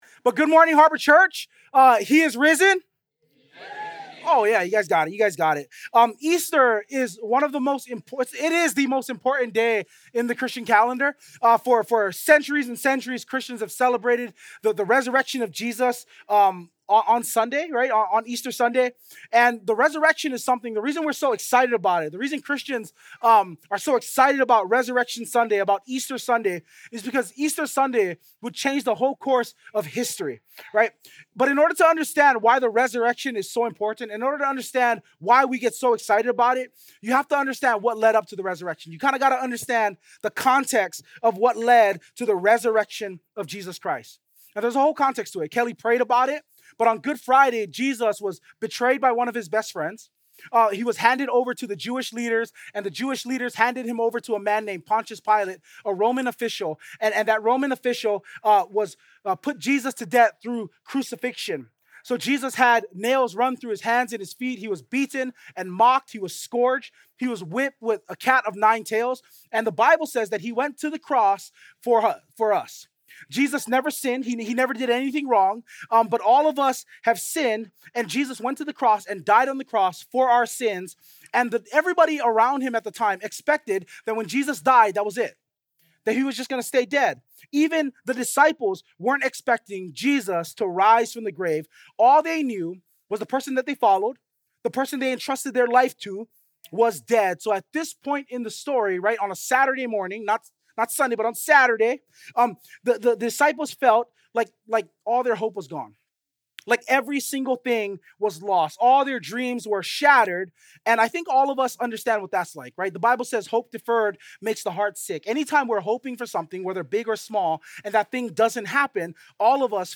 Life Transformed | Harbor Church Waipahu